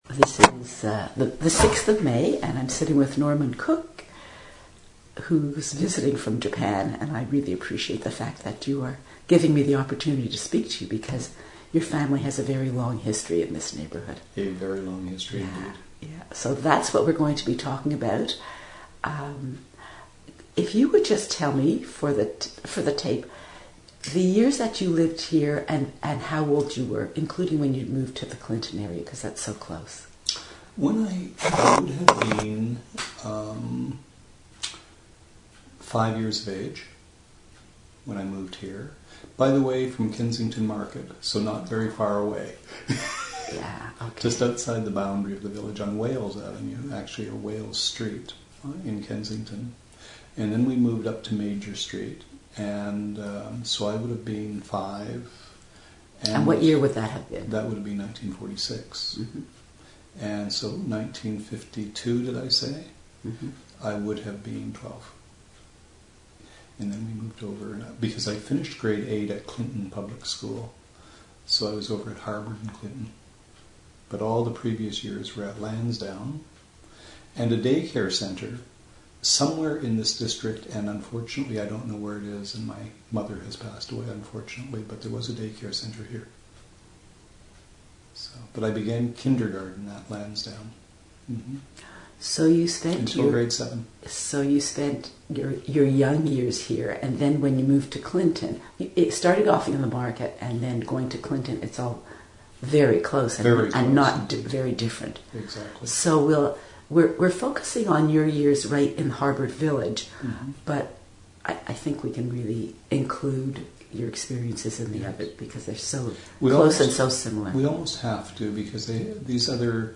Harbord Village Oral History Project 2013